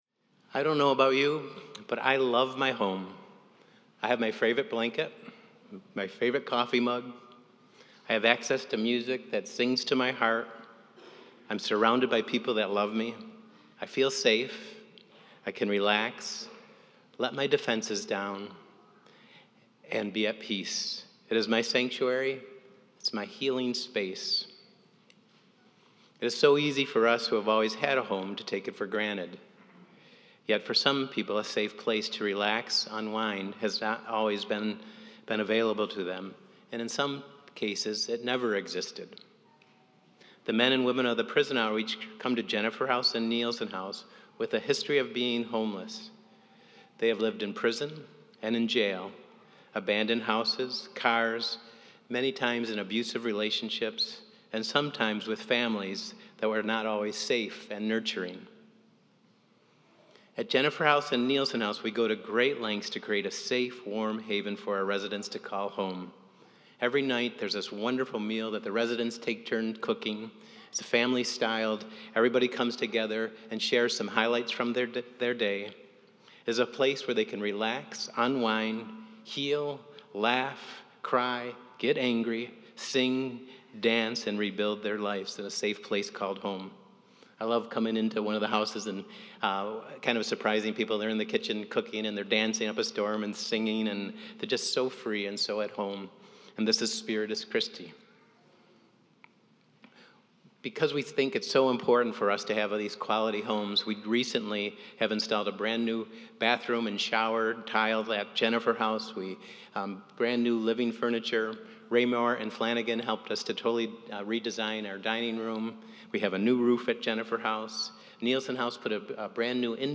Nielsen and Jennifer house alumni share their stories and how the love and support of the Prison Outreach Program has helped them along the way.